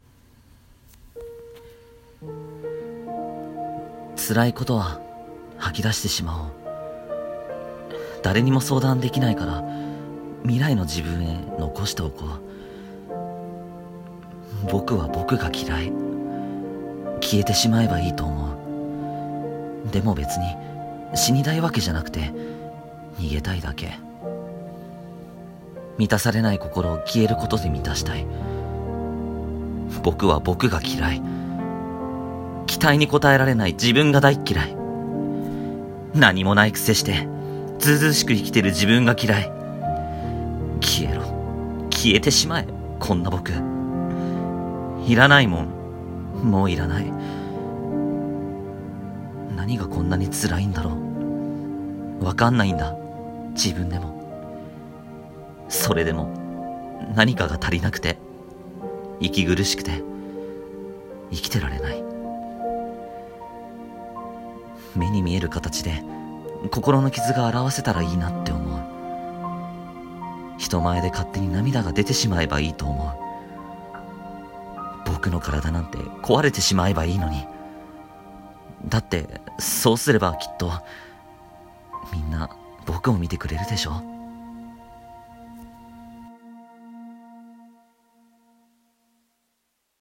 一人声劇